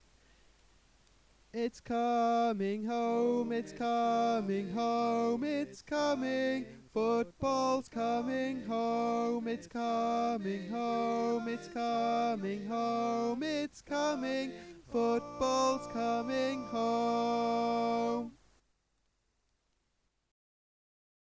Key written in: B♭ Major
Type: Barbershop